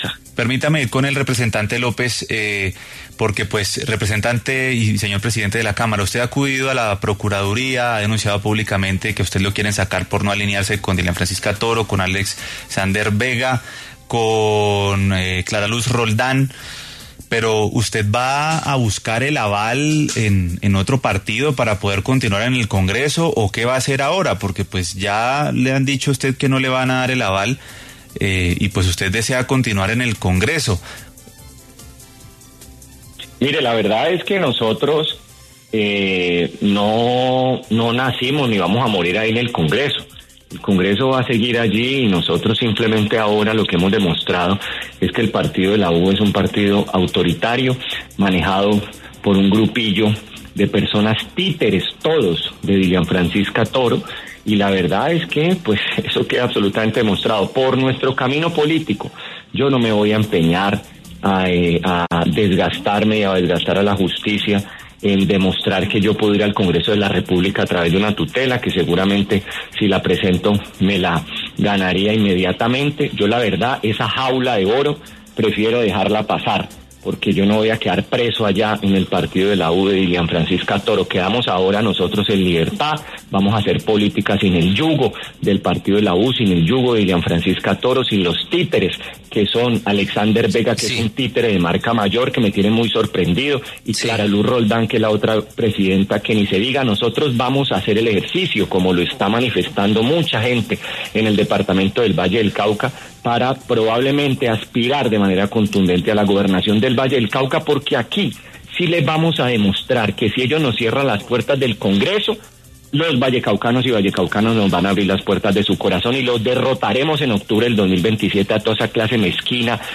Este domingo, 7 de diciembre, en diálogo con W Fin De Semana, el presidente de la Cámara de Representantes, Julián López, habló sobre la decisión que tomó el Partido de la U de negarle el aval para las elecciones de 2026, en medio de una fuerte tensión con la directora de la colectividad, Dilian Francisca Toro, también gobernadora del Valle del Cauca.